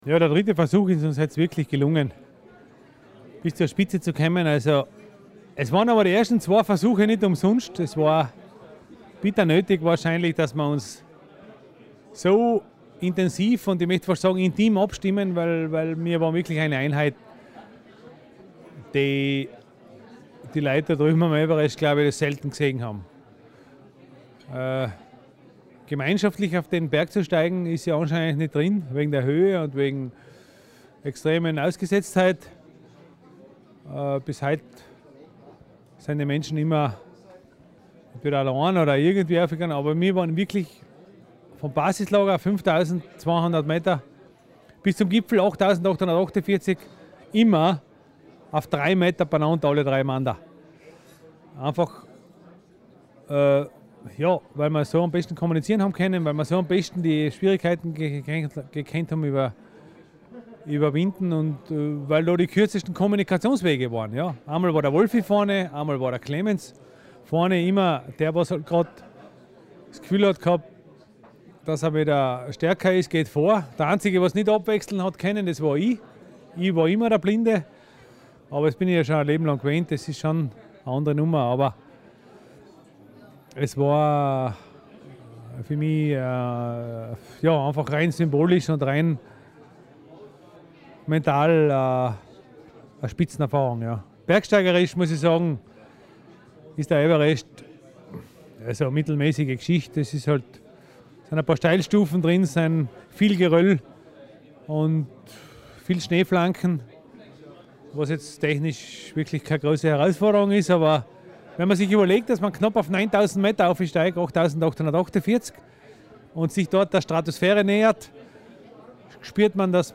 Die Bilder vom Everest-Gipfelsieg und vom Empfang. Plus: Ausführliches Interview!